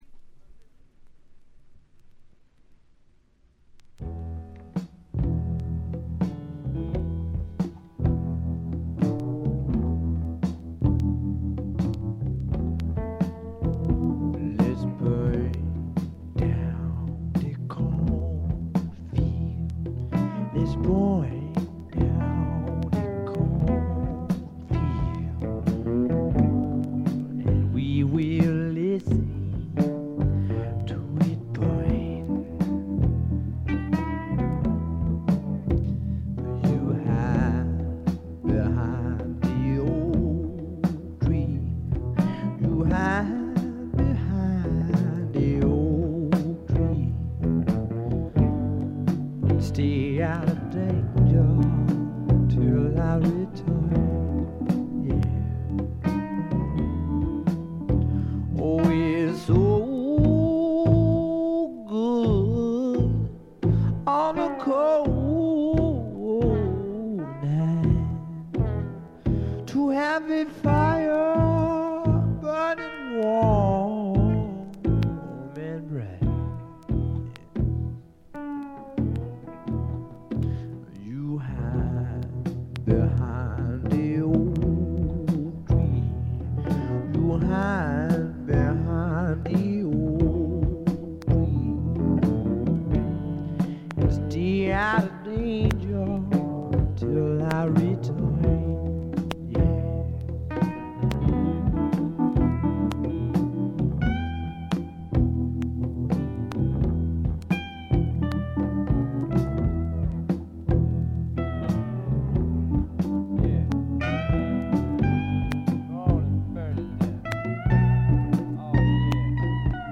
A2傷部分中盤以降間欠的に周回ノイズ。
試聴曲は現品からの取り込み音源です。